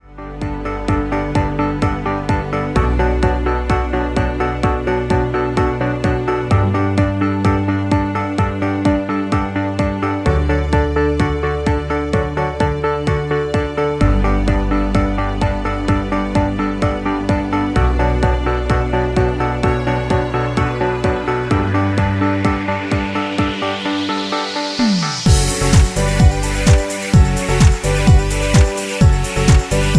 Backing track files: 2010s (1044)
Buy Without Backing Vocals